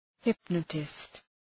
Προφορά
{‘hıpnətıst}